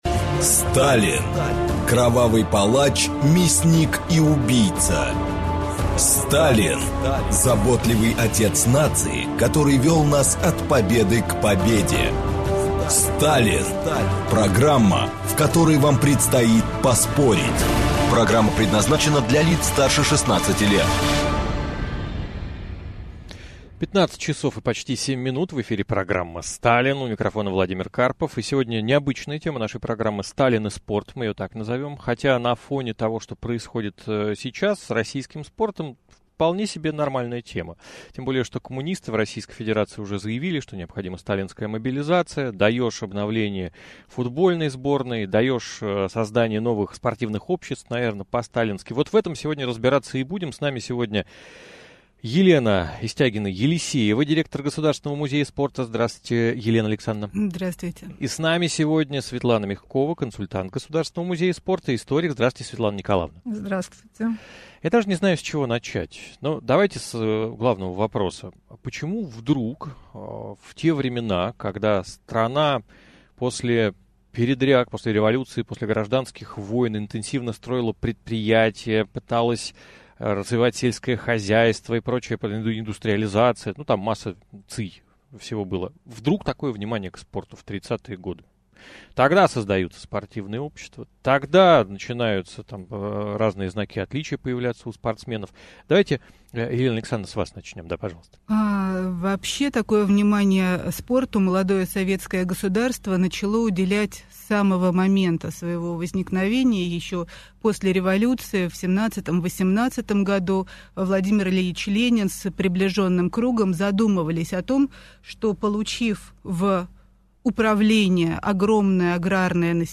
Аудиокнига Сталин и советский спорт | Библиотека аудиокниг
Прослушать и бесплатно скачать фрагмент аудиокниги